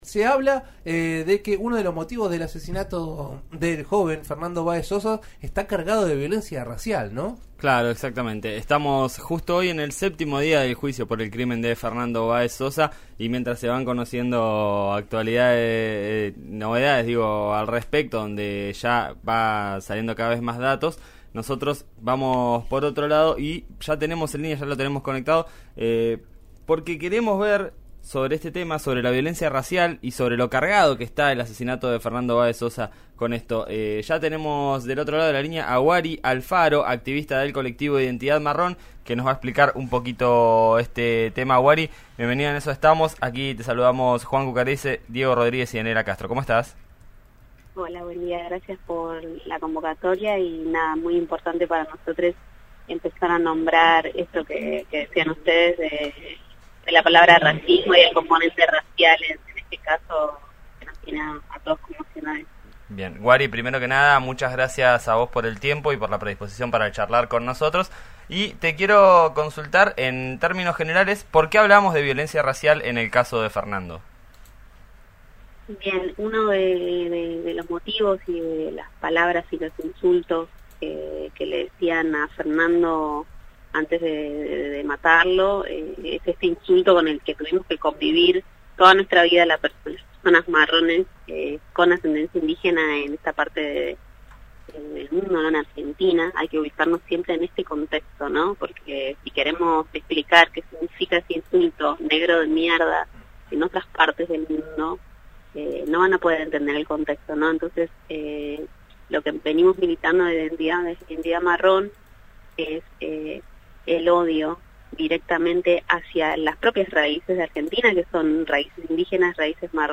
Integrante de colectivo Identidad Marrón habló con RÍO NEGRO RADIO. Advirtió que el racismo en este país 'es estructural'.